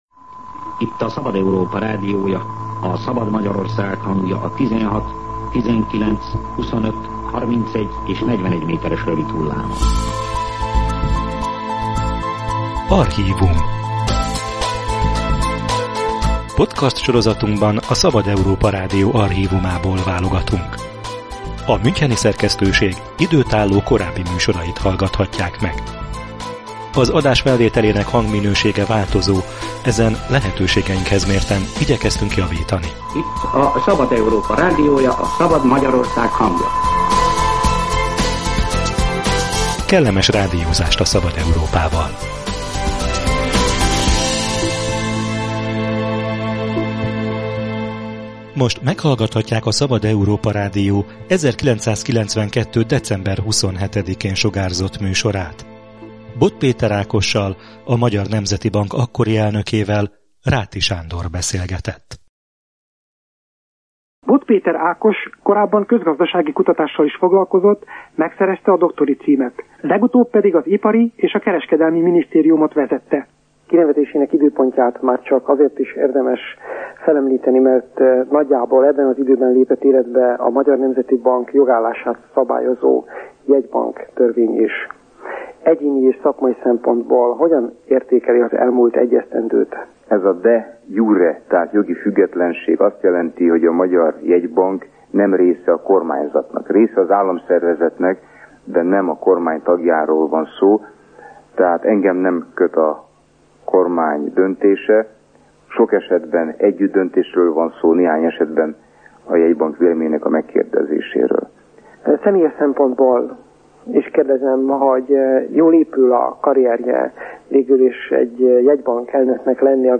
Ebben az időszakban készült a Szabad Európa Rádió interjúja Bod Péter Ákossal, a jegybank akkori elnökével. Szóba kerül, miben különbözik munkája és szakértelme egy politikusétól.